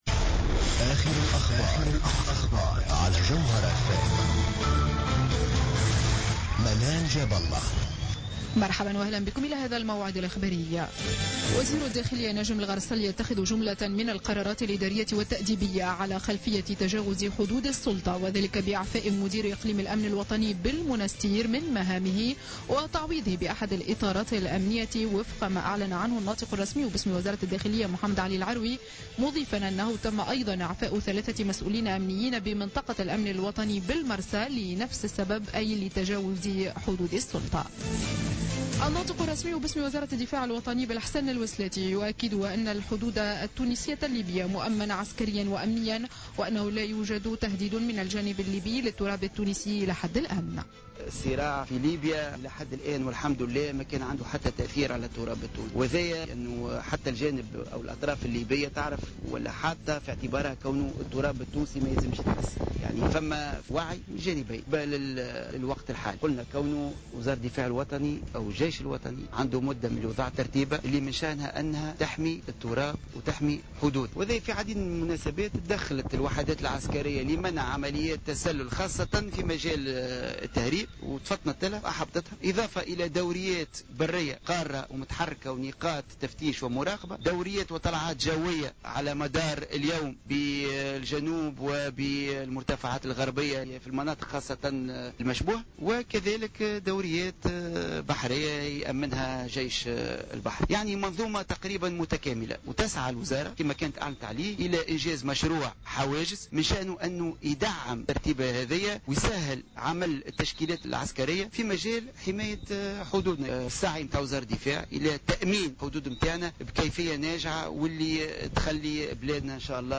نشرة أخبار منتصف الليل ليوم الجمعة 26 جوان 2015